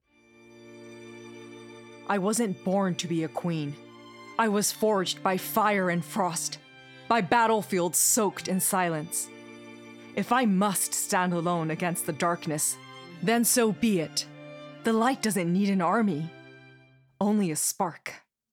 – 声優 –
堂々とした女性